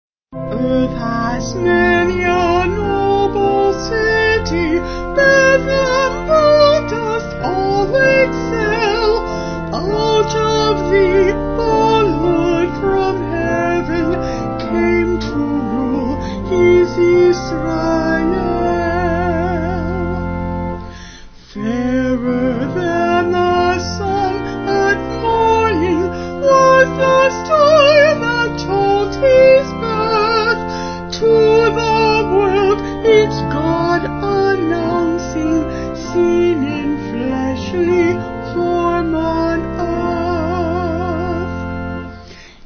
Vocals and Organ
250kb Sung Lyrics